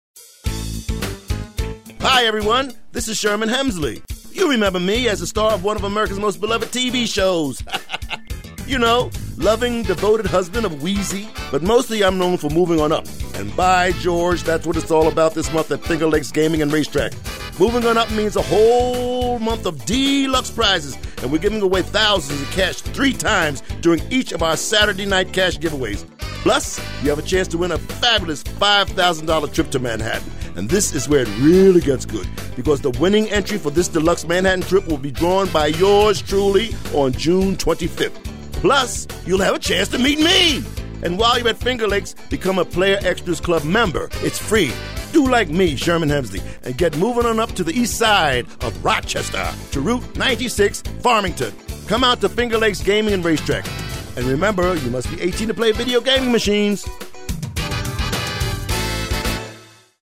Radio
Movin' On Up. Written for a casino promotion. Features the actor who played "George Jefferson"--much nicer in reality than the character he played. 60 seconds.